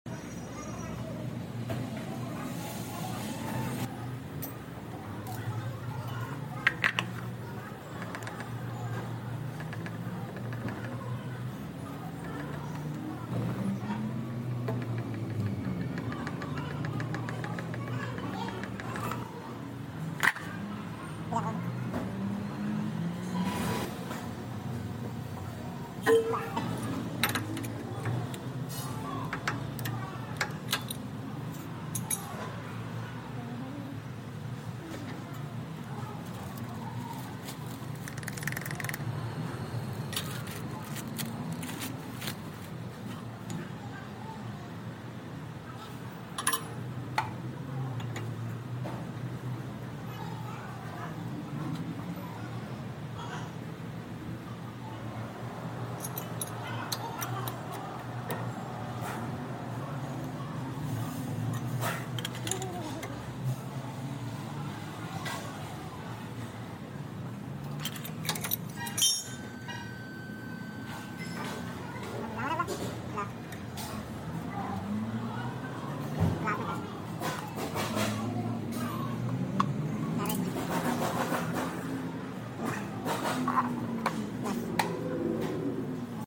Inner rod sound change new sound effects free download